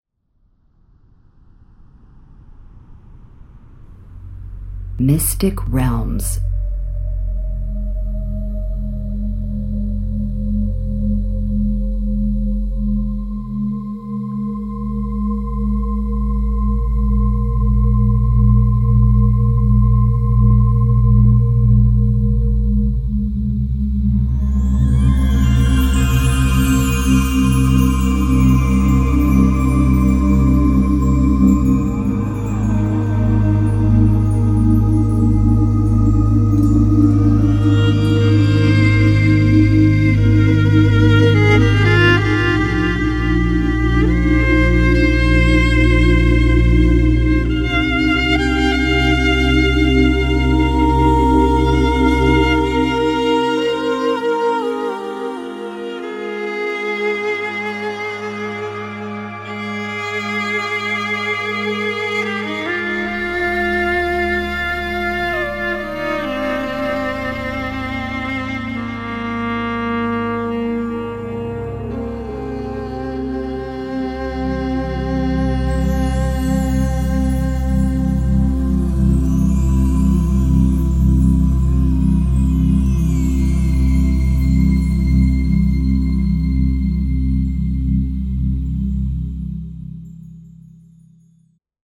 fascinující obklopující hudba
Relaxace, Meditace, Relaxační a Meditační hudba
Verbální vedení: Neverbální
relaxační hudba